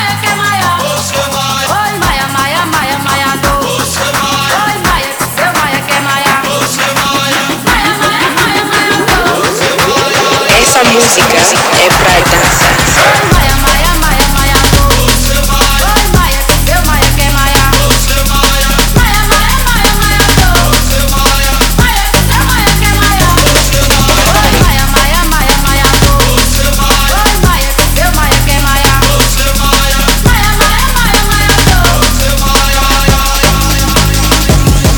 Жанр: Танцевальные
Jungle, Drum'n'bass, Dance